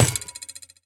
ground_impact4.ogg